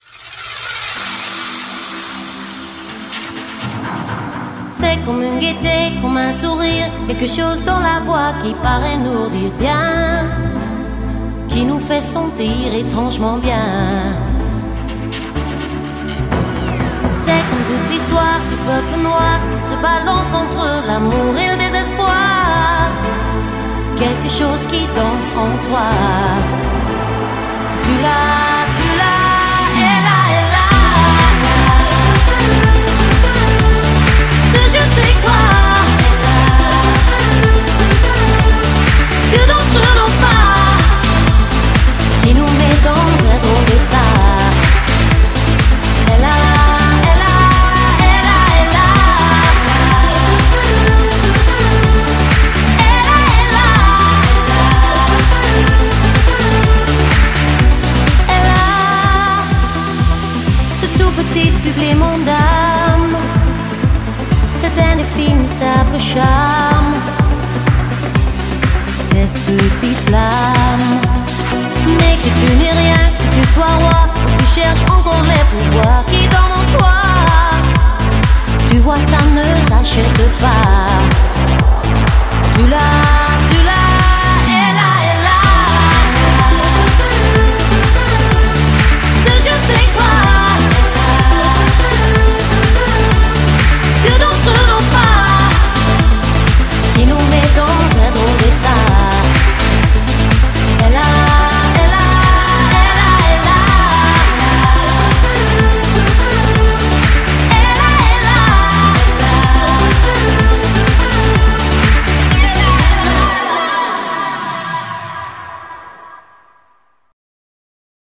BPM125
Audio QualityPerfect (High Quality)